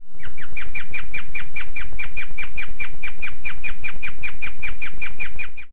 • Chirping, a less common sound likened to bird song, seems to be related to stress or discomfort or when a baby guinea pig wants to be fed.
Guinea_Pig_Chirping.ogg.mp3